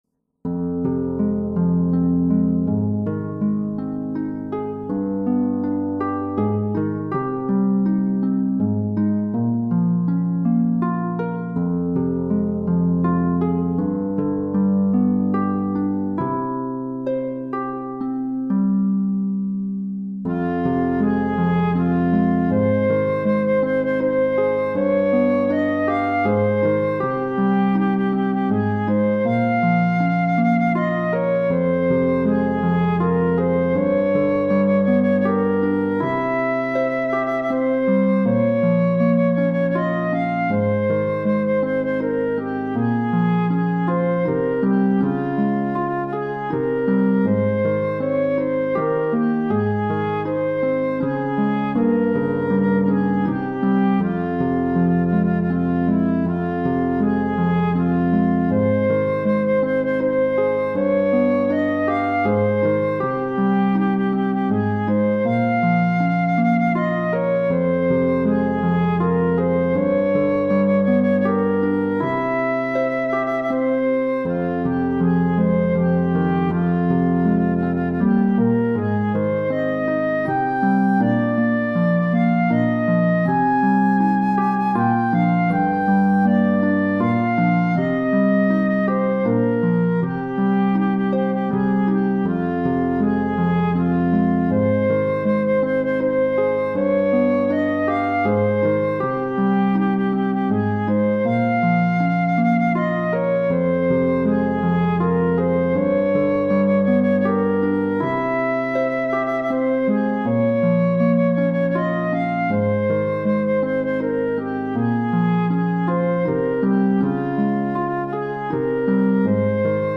Sheet music for the Flute Part